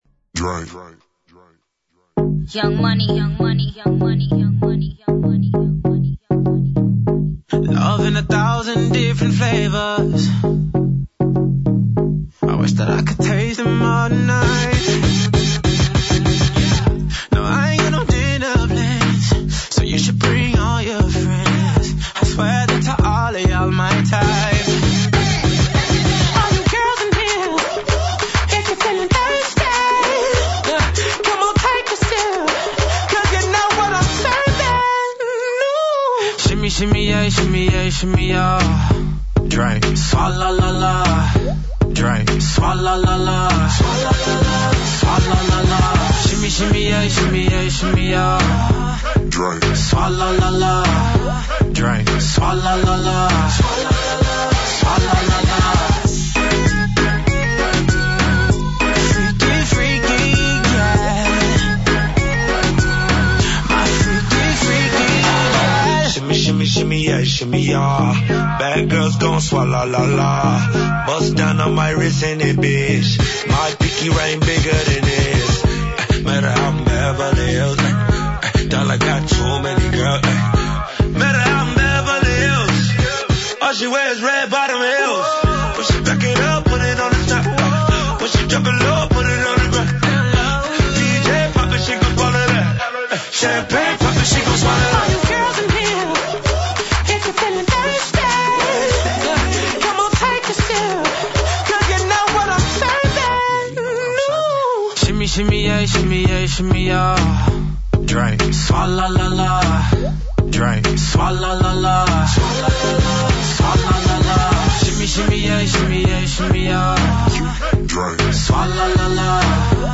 електронске плесне музике